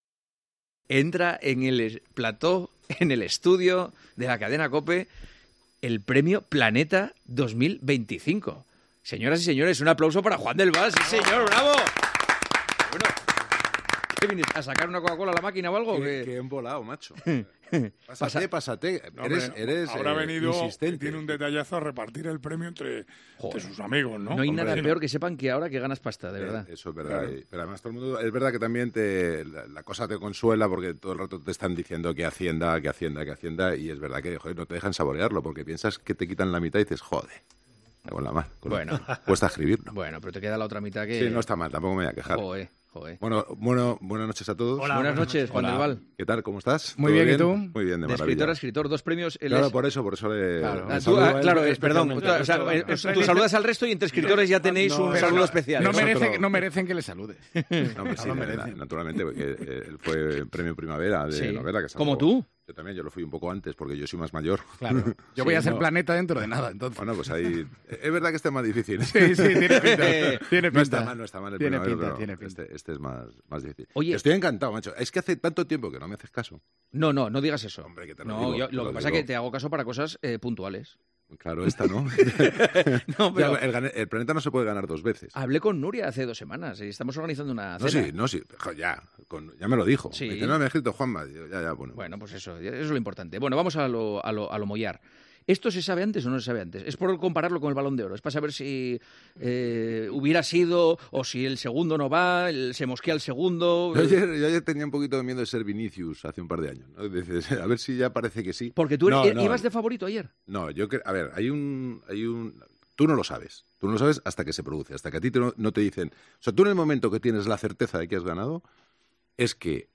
Fragment d'una entrevista a Juan del Val, recent premi Planeta.
Esportiu